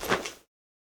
equip_leather1.ogg